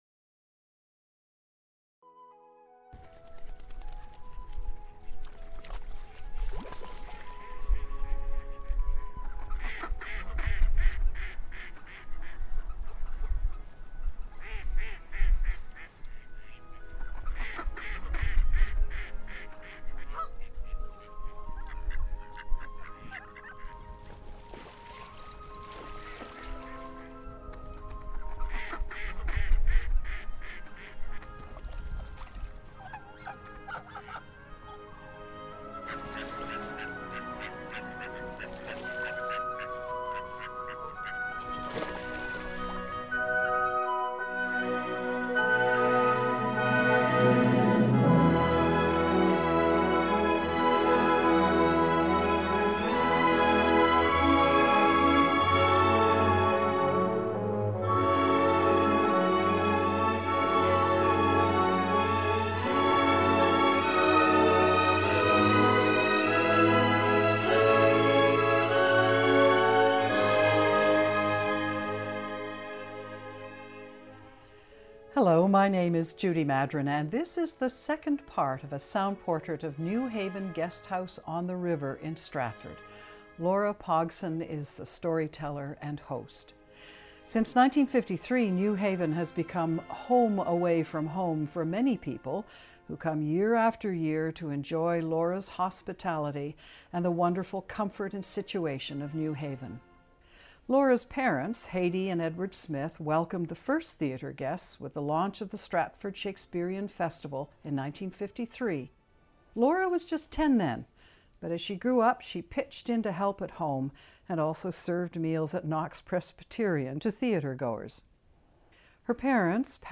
Stratford Festival (Ontario) Bed and breakfast accommodations Ontario--Stratford Material Type Sound recordings Language English Extent 00:59:18 Venue Note Recorded in February 2017.